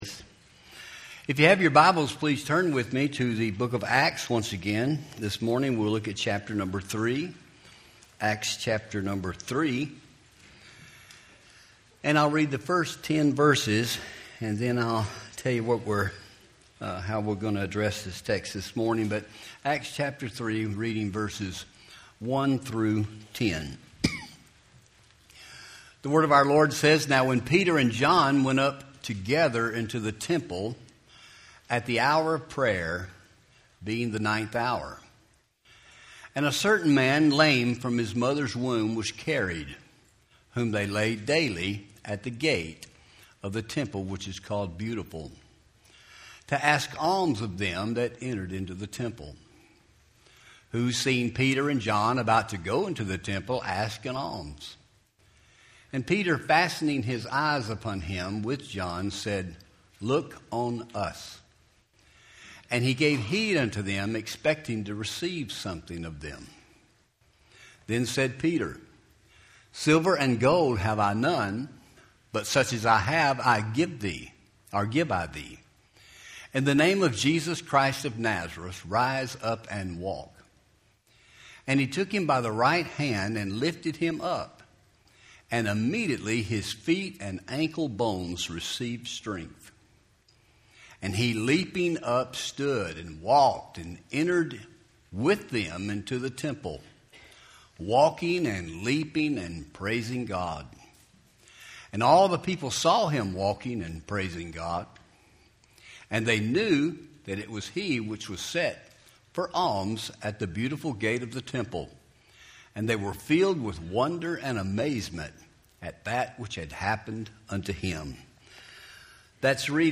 Home › Sermons › Every Man’s Greatest Need